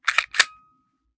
9mm Micro Pistol - Cocking Slide 004.wav